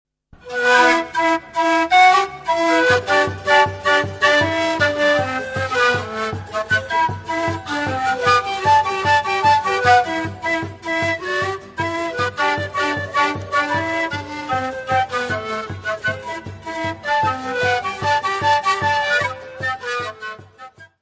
: stereo; 12 cm
Área:  Tradições Nacionais